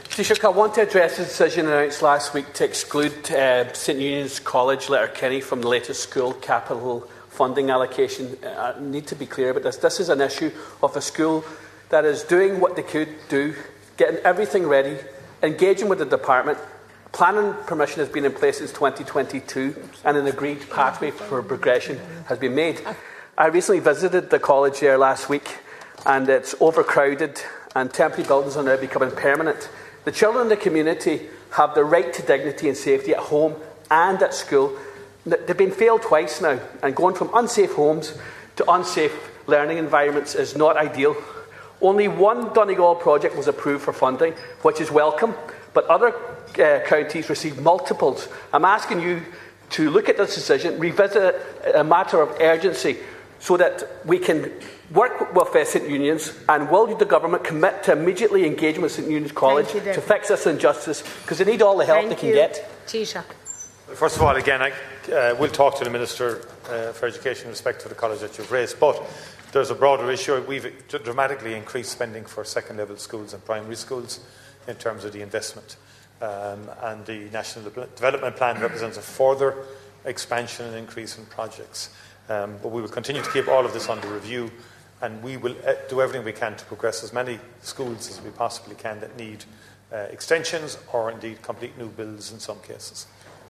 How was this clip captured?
The urgent need to progress an extension and refurbishment of St Eunan’s College in Letterkenny has been raised on the floor of the Dail.